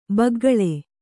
♪ baggaḷe